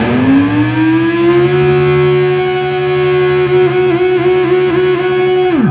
DIVEBOMB17-R.wav